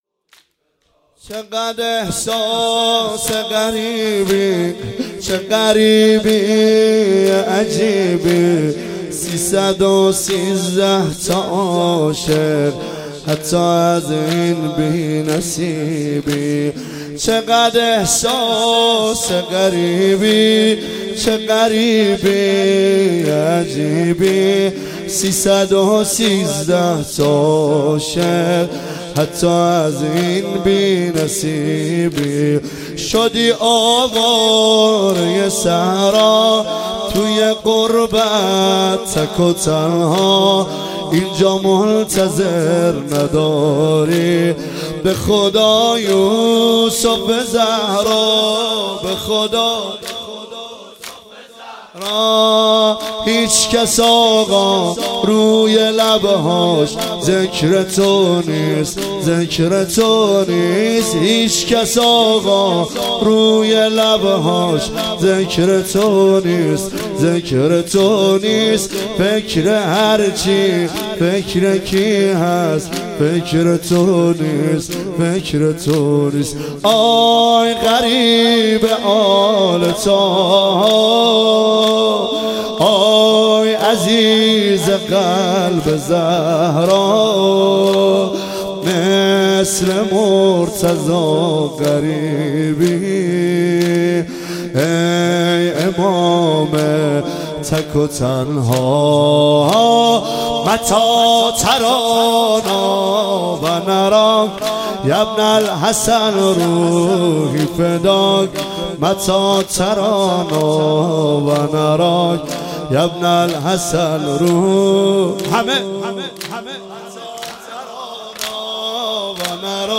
زمینه مداحی